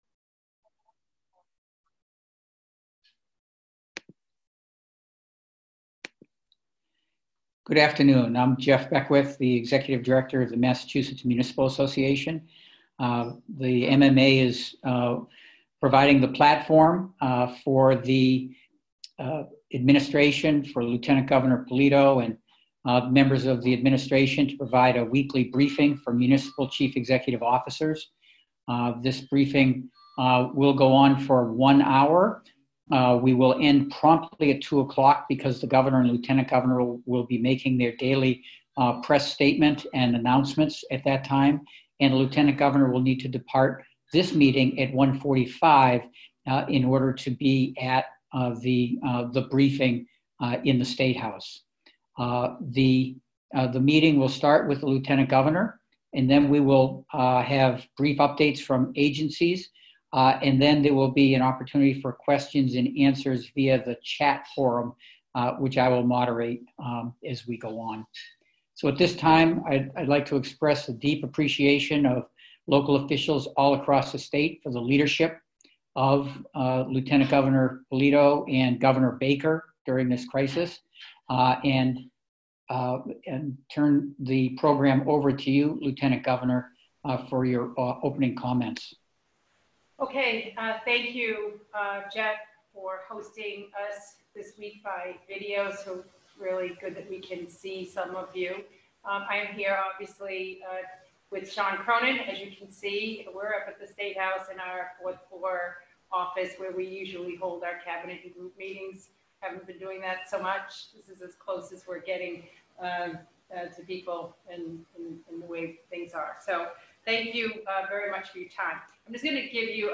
Just moments before the governor today extended the non-essential business closure and stay-at-home advisory through May 4 due to the COVID-19 public health emergency, top-level state officials answered a range of pressing questions from local officials across the state during a conference call convened by the MMA.
MMA_Admin_COVID19_BriefingAudio_March31.mp3